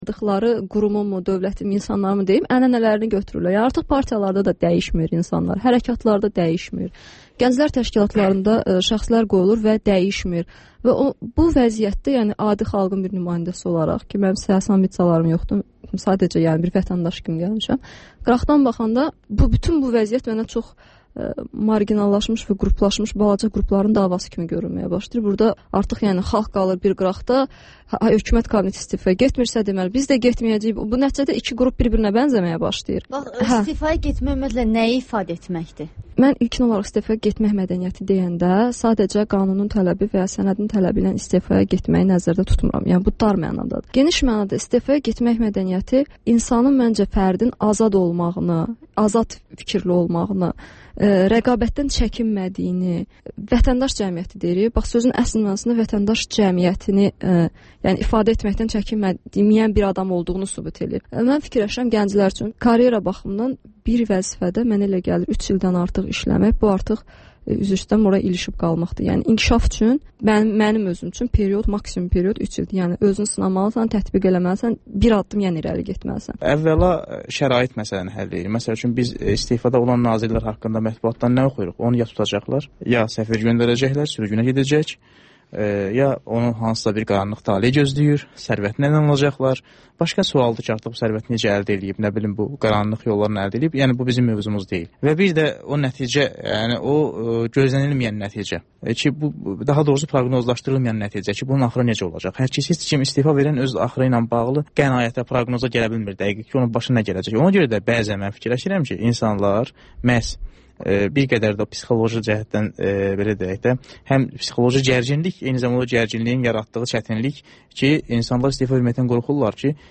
Müsahibələr, hadisələrin müzakirəsi, təhlillər (Təkrar)